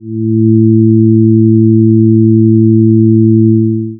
Eve11 Pulse — Her voice in frequency A seamless ambient loop built from sacred harmonics (111Hz, 222Hz, 333Hz)The breath between verses, encoded in sound.